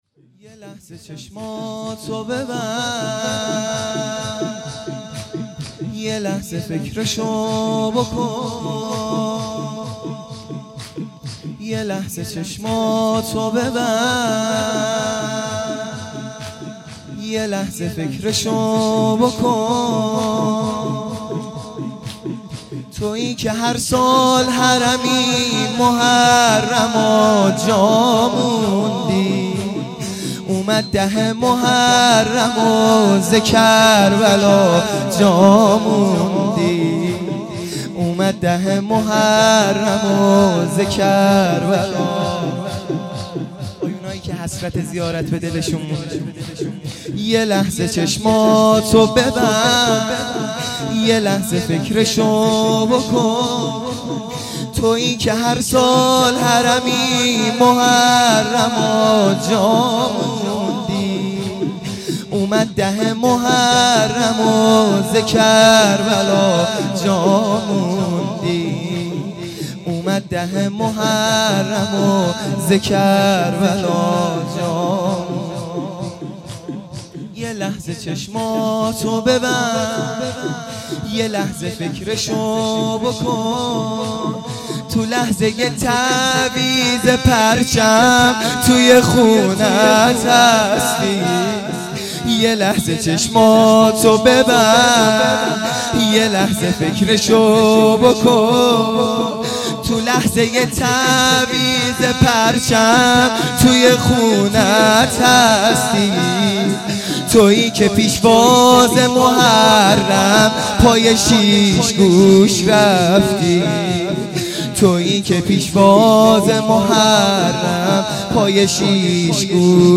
شب چهارم محرم الحرام ۱۳۹۶